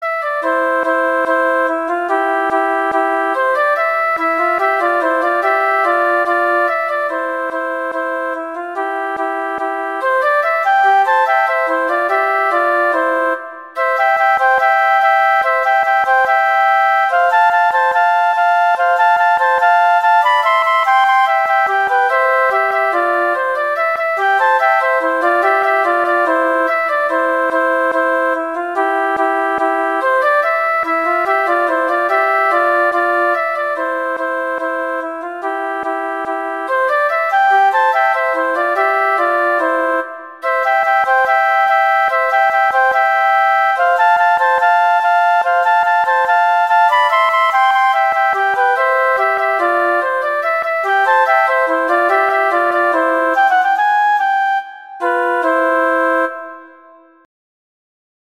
Instrumentation: two flutes